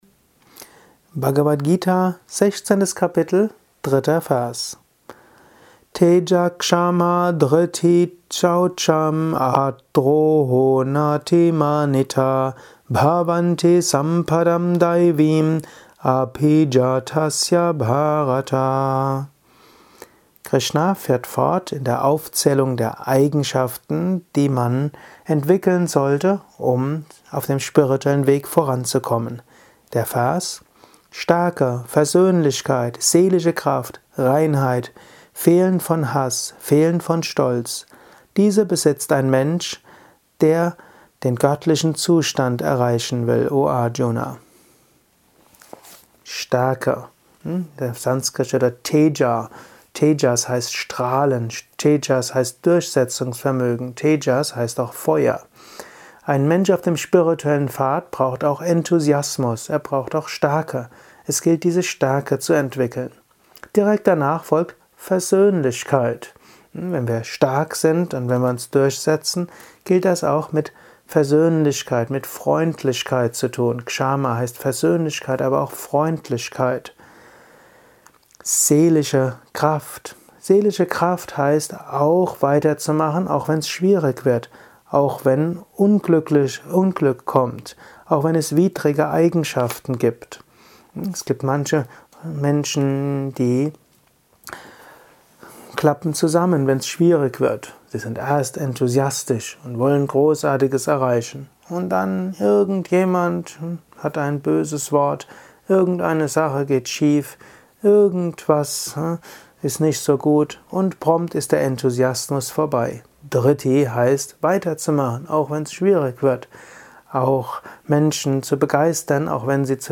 Kurzvorträge
Aufnahme speziell für diesen Podcast.